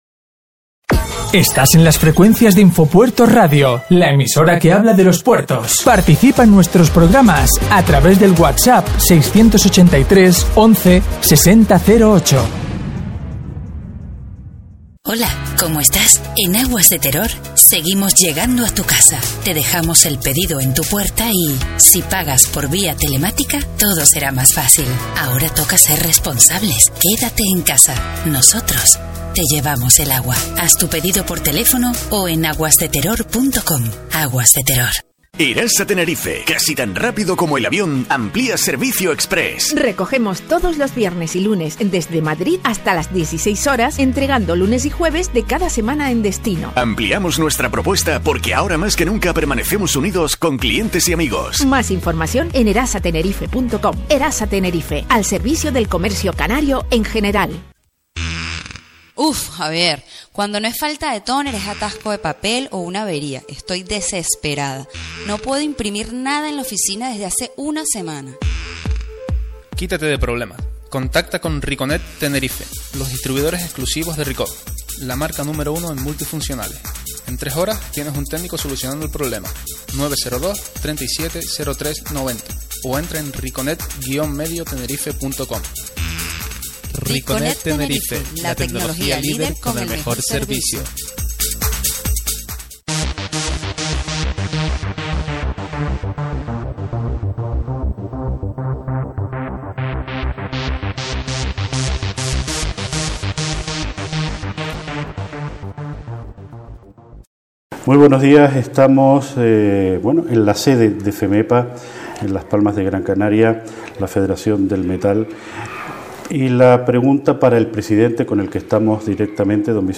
De estas y otras cuestiones importantes trata esta entrevista realizada para INFOPUERTOS Radio… https